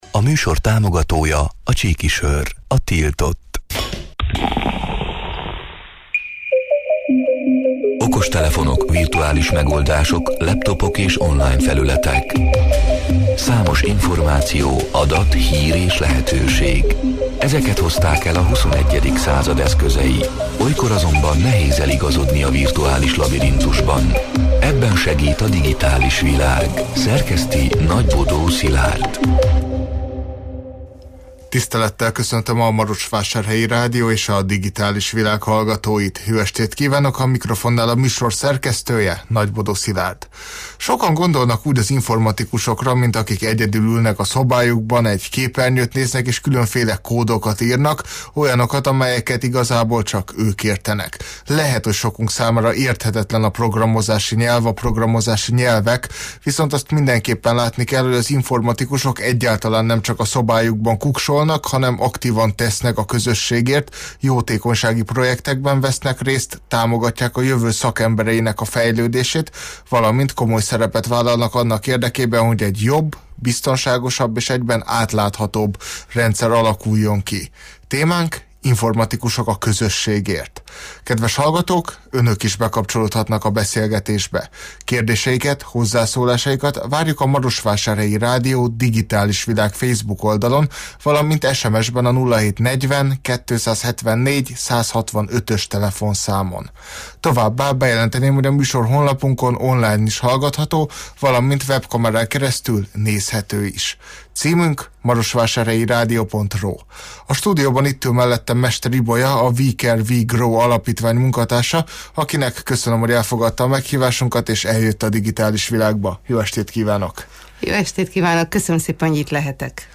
A Marosvásárhelyi Rádió Digitális Világ (elhangzott: 2024. december 3-án, kedden este nyolc órától élőben) c. műsorának hanganyaga: